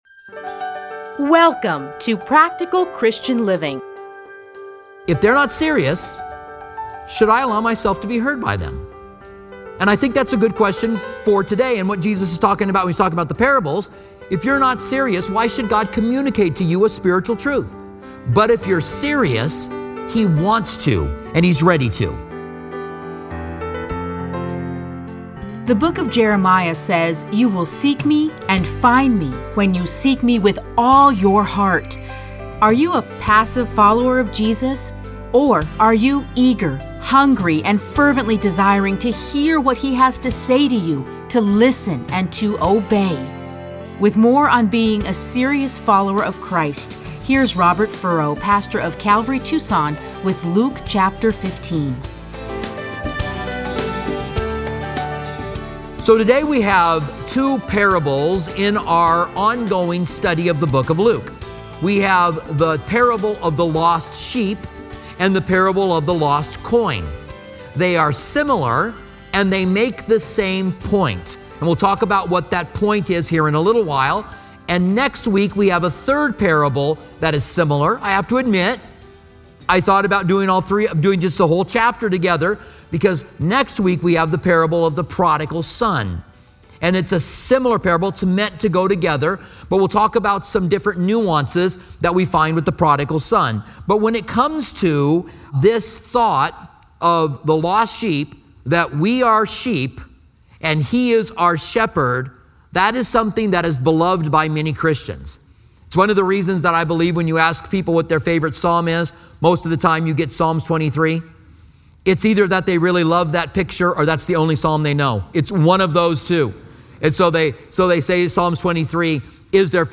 Listen to a teaching from Luke 15:1-7.